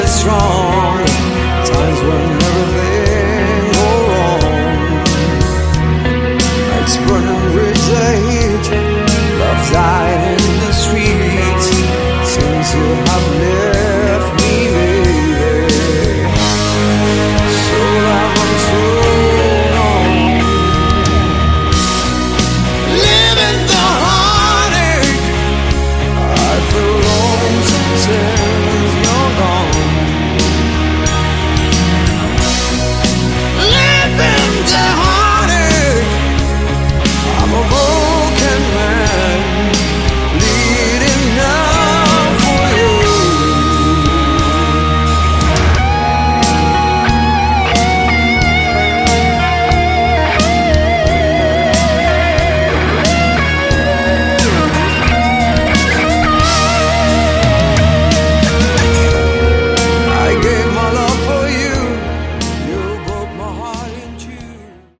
Category: Hard Rock
guitars
keyboards
drums, backing vocals